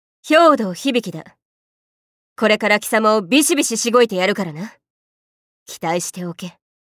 自己紹介編ボイス